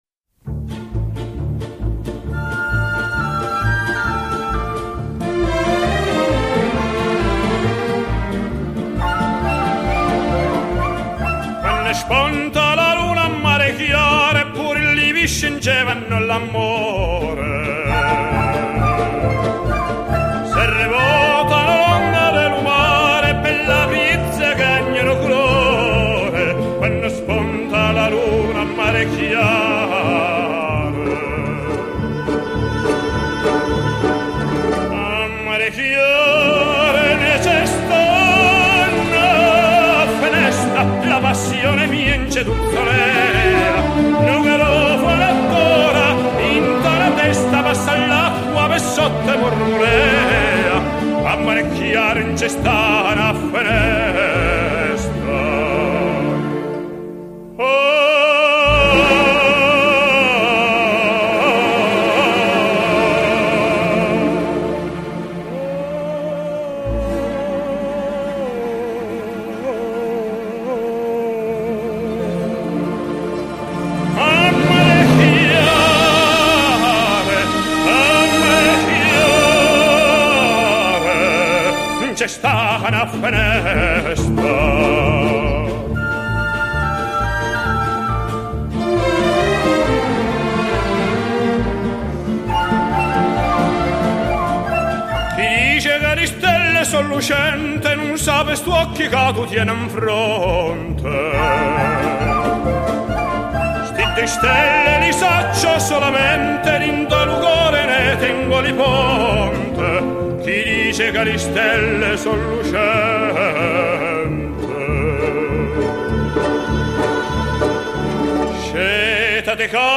由于原录音是1962